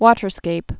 (wôtər-skāp, wŏtər-)